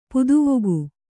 ♪ puduvugu